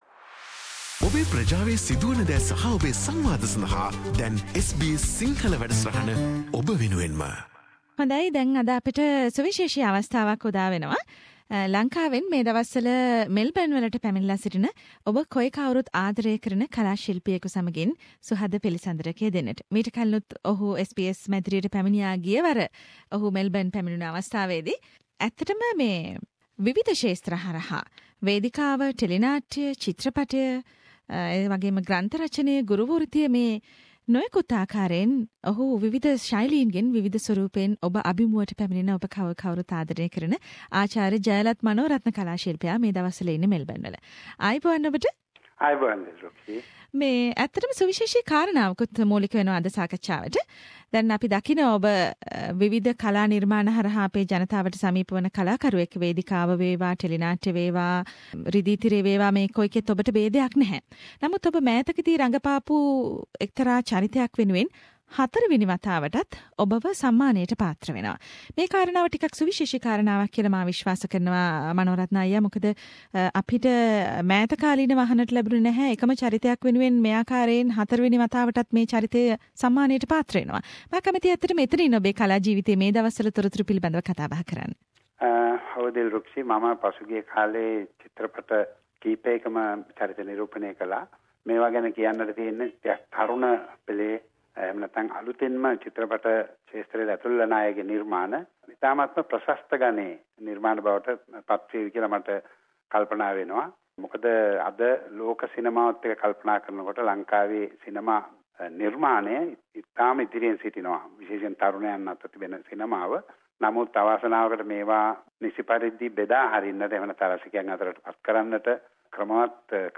Renowned drama artist, film actor Dr; Jayalath Manorathna spoke to the SBS Sinhalese program regarding one of his authentic film characters which has been recently awarded for the fourth time as the best co character in the main film award ceremonies in Sri Lanka.